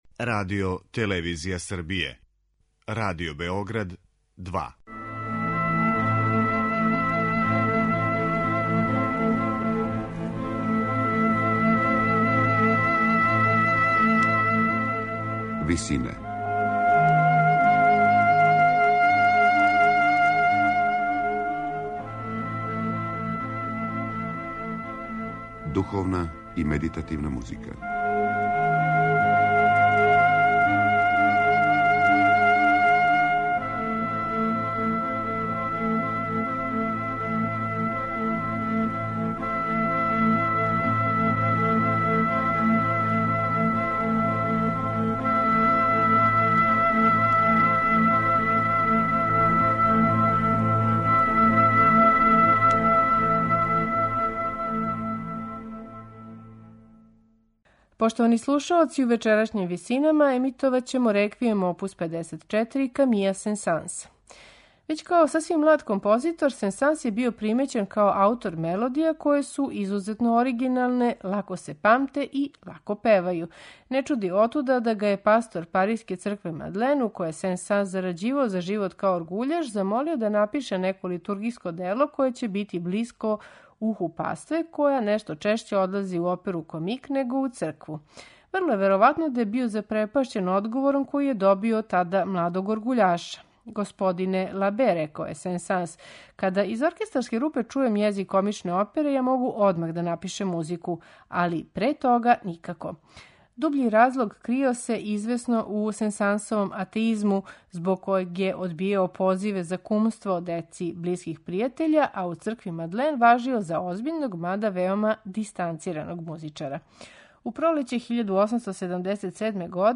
сопран
мецосопран
тенор
бас
медитативне и духовне композиције